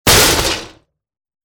vehicle
Crash.wav